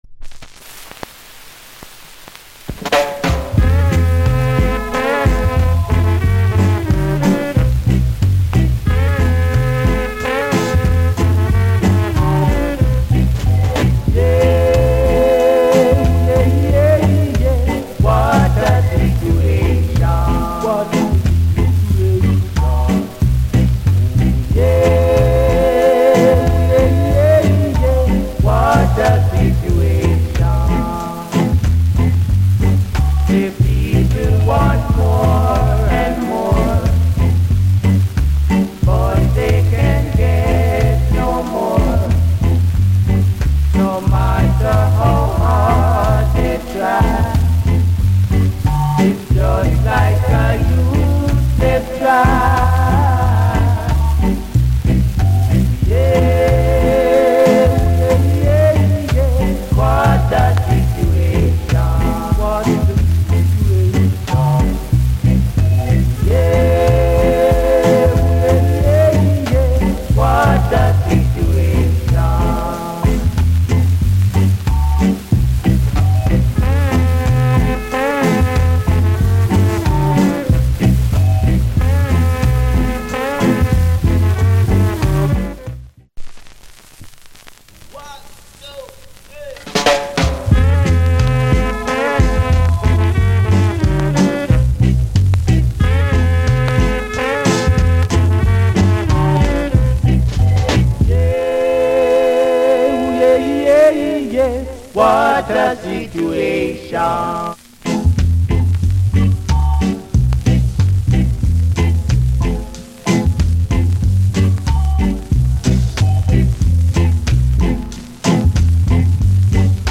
* ヒスノイズあり。